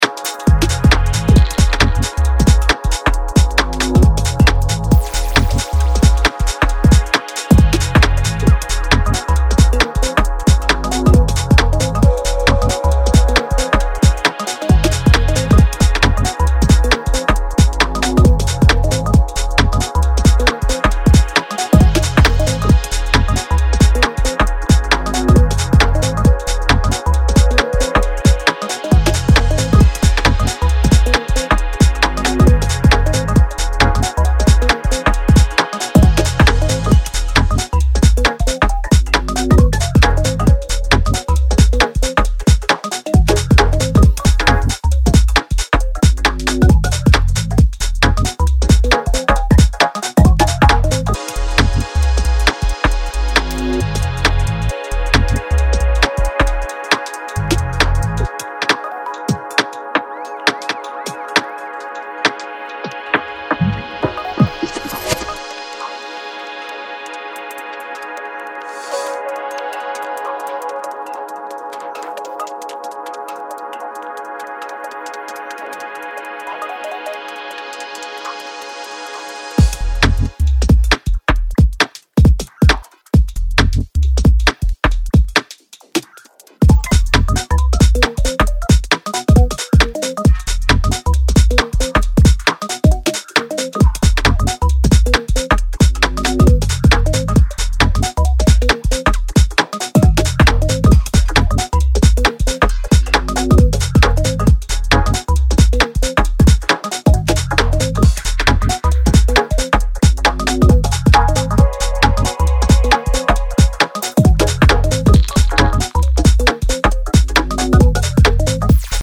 Breaks infused UKG